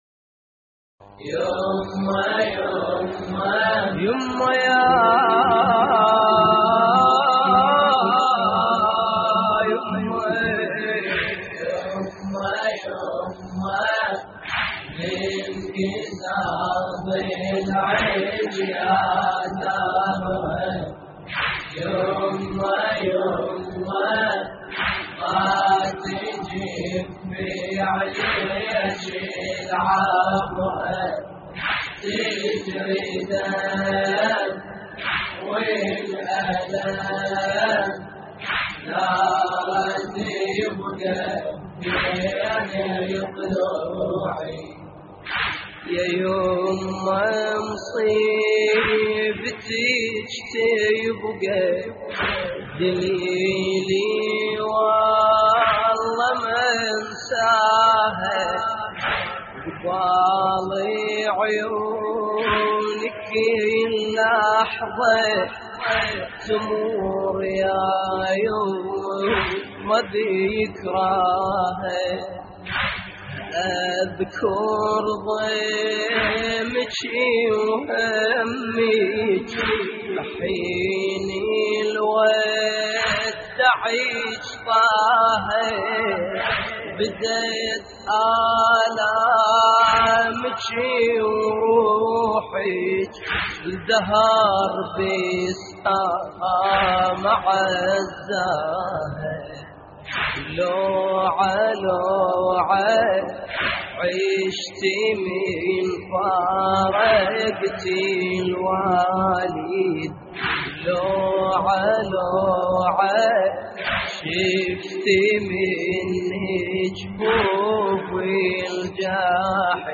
اللطميات الحسينية
موقع يا حسين : اللطميات الحسينية يمه يمه انكسر ضلعك يا زهرة - استديو «قال سليم» لحفظ الملف في مجلد خاص اضغط بالزر الأيمن هنا ثم اختر (حفظ الهدف باسم - Save Target As) واختر المكان المناسب